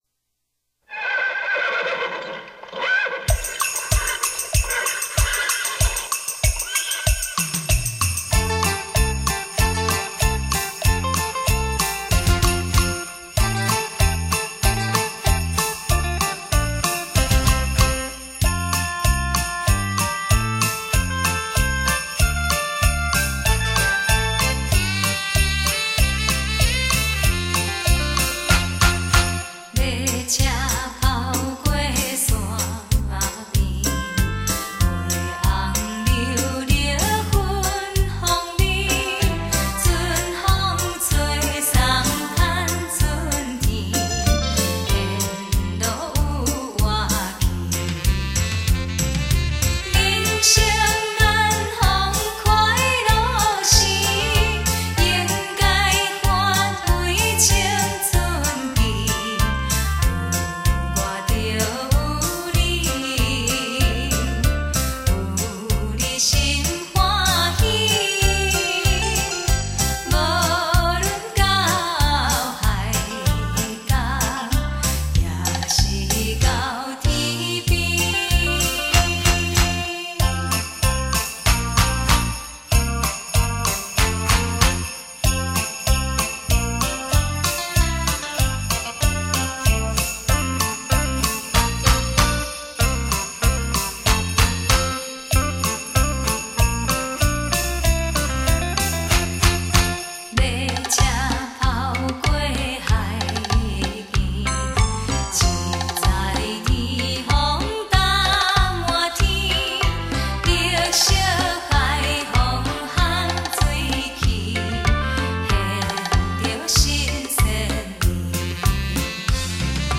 恰恰 慢四步 吉鲁巴 探戈
(吉鲁巴)